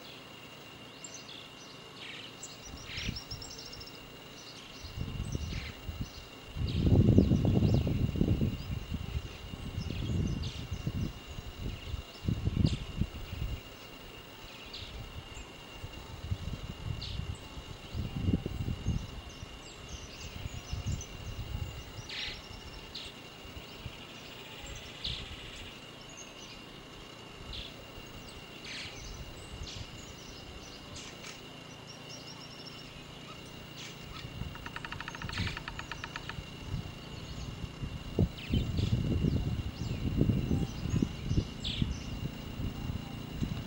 Golondrina Negra (Progne elegans)
Se escucha tambien Melanerpes cactorum
Localidad o área protegida: Colalao del Valle
Condición: Silvestre
Certeza: Filmada, Vocalización Grabada